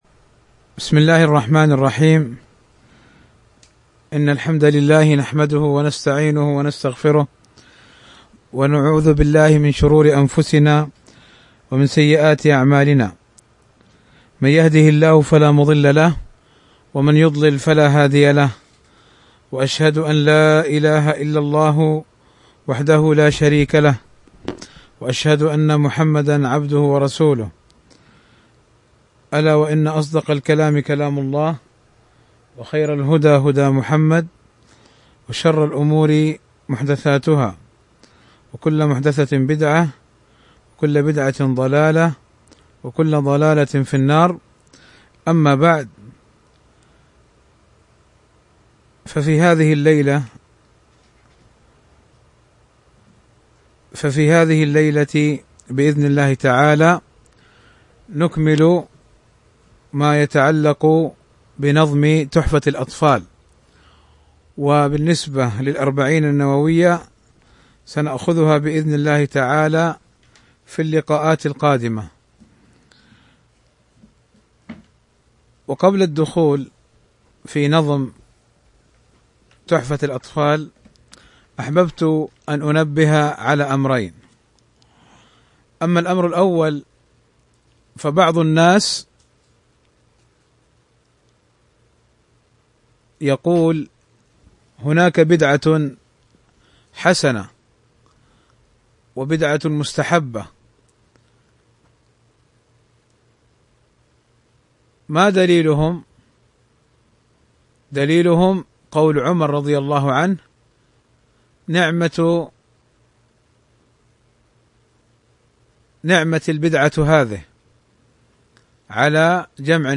شرح تحفة الأطفال الدرس 9